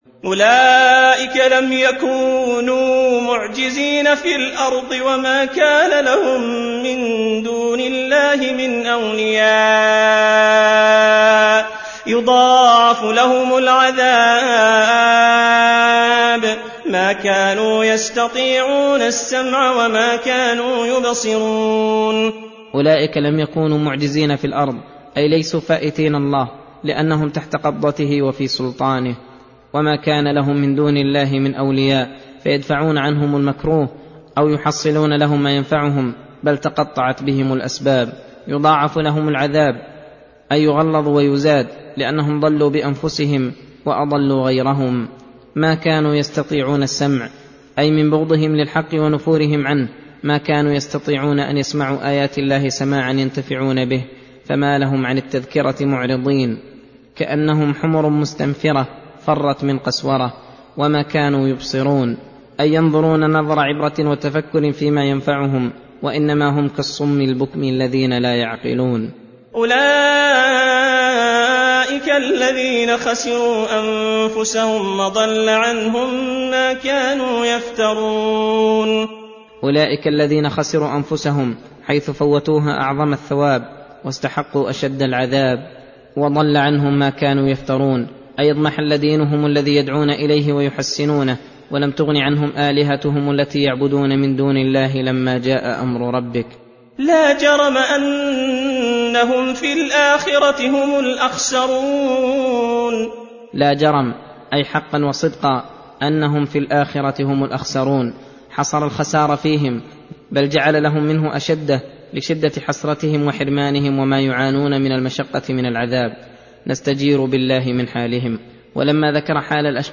درس (32) : تفسير سورة هود : (20 - 35)